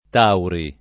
Tauri [ t # uri ] top. m. pl. (Austria)